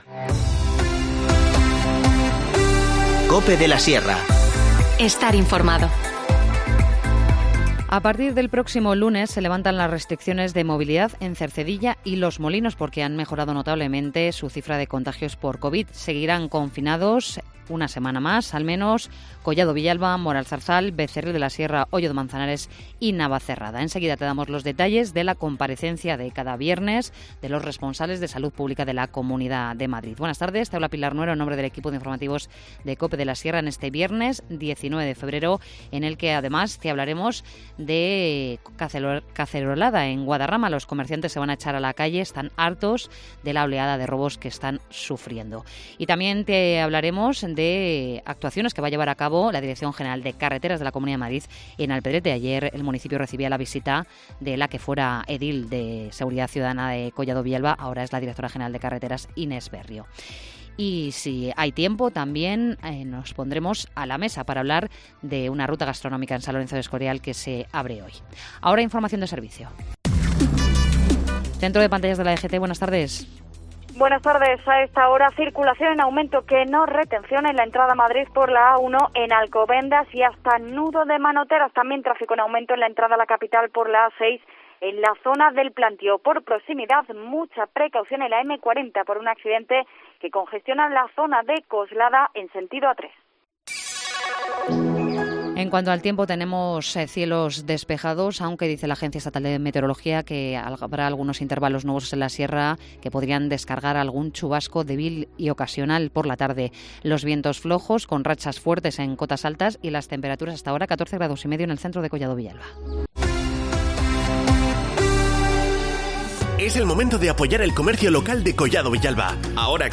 Informativo Mediodía 19 de febrero